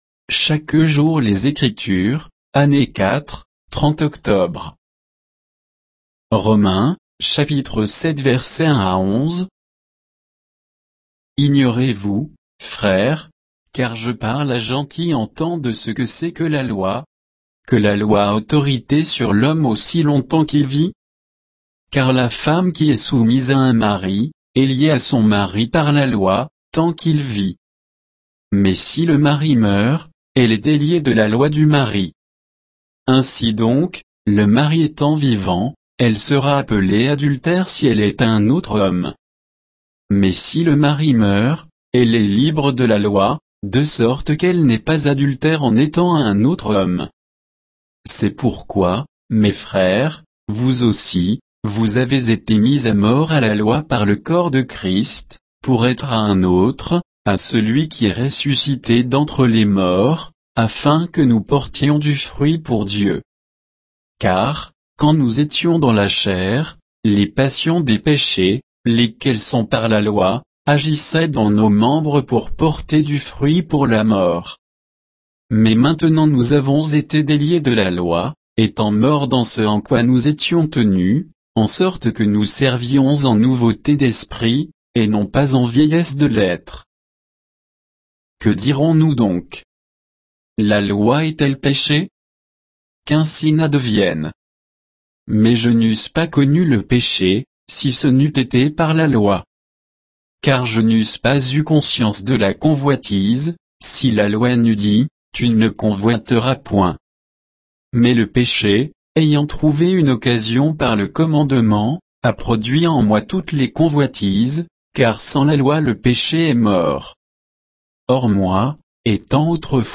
Méditation quoditienne de Chaque jour les Écritures sur Romains 7